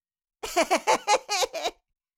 Cartoon Little Child, Voice, Laugh, Laughter 3 Sound Effect Download | Gfx Sounds
Cartoon-little-child-voice-laugh-laughter-3.mp3